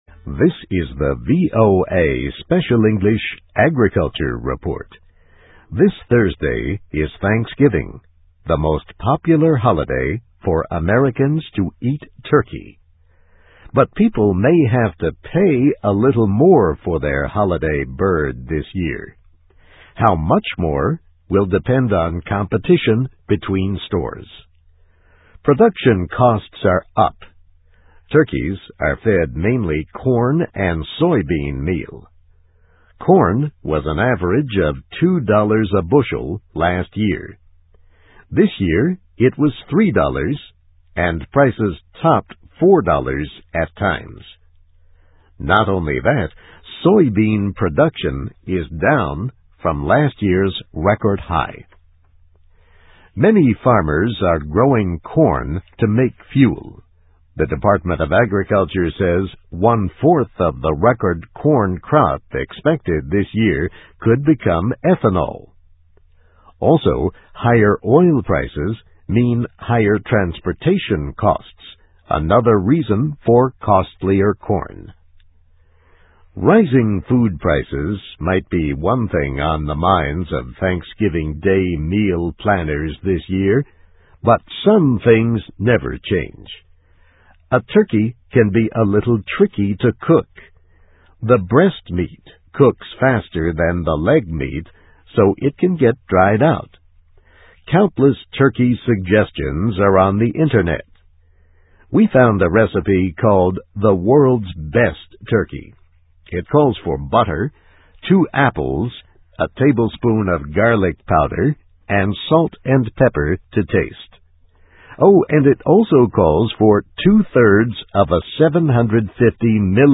Animals: Turkey Production Costs Are Up; Not Good News at Thanksgiving (VOA Special English 2007-11-19)